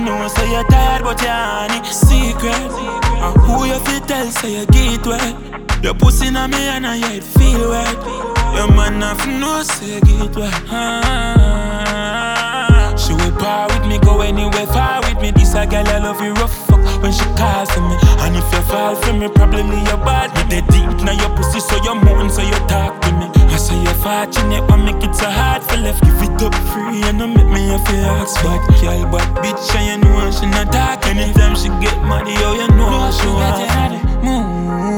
Off-beat гитары и расслабленный ритм
Modern Dancehall Reggae
2025-06-20 Жанр: Регги Длительность